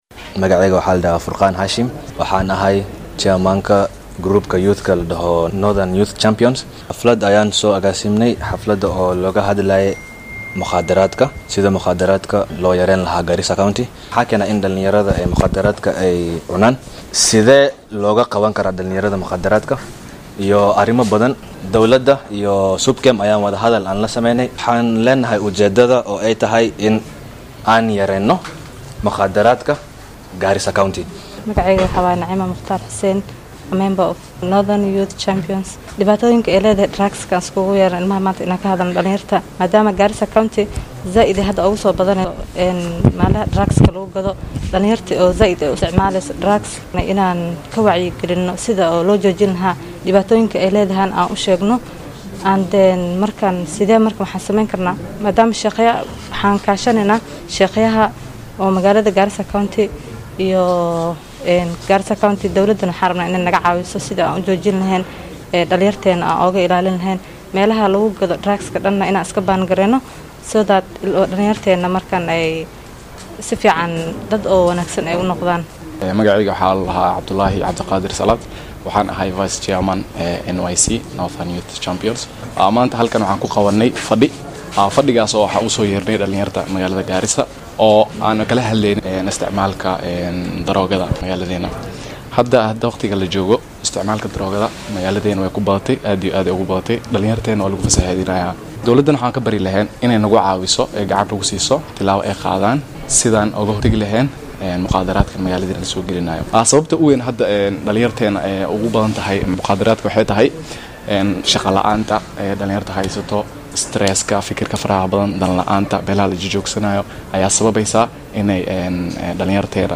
Qaar ka mid ah dhallinyarada shirka qabtay ayaa u warramay warbaahinta Star.